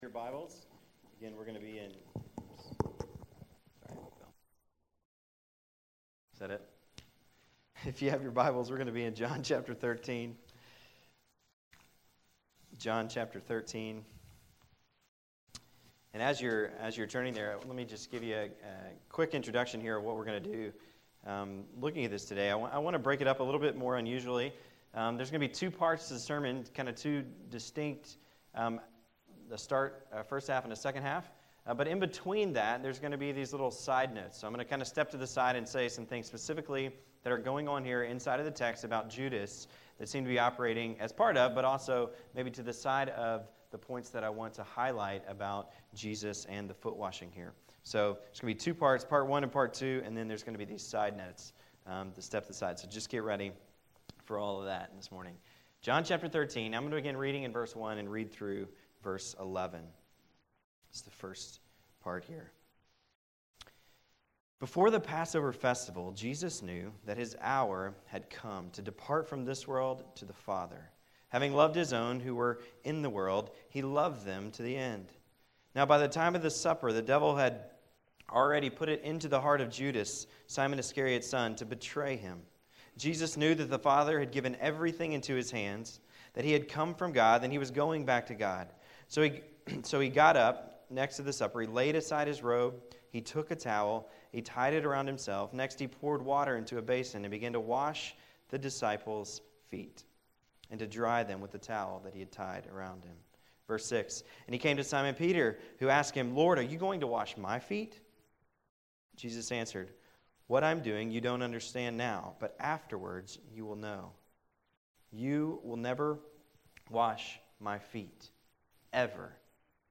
This is the next message in sermon series covering the book of John.